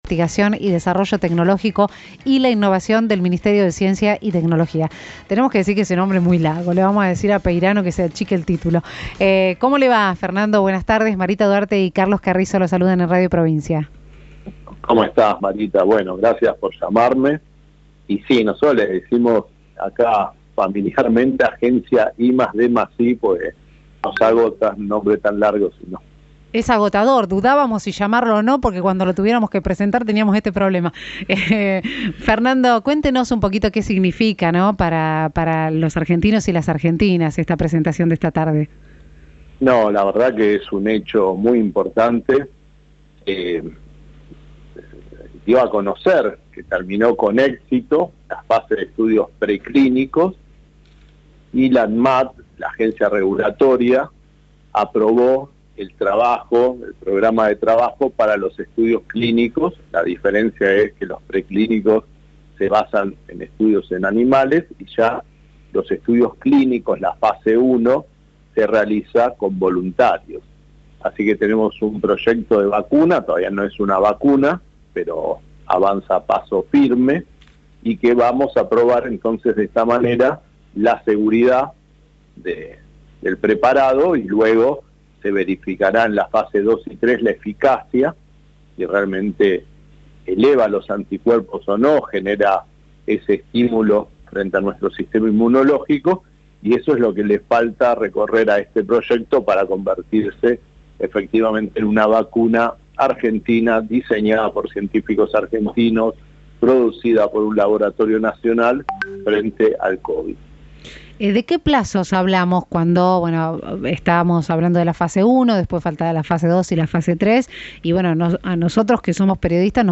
En diálogo con “Radio Provincia”, el funcionario explicó qué significa que la primera vacuna argentina contra el coronavirus haya comenzado la Fase I de estudios clínicos en humanos.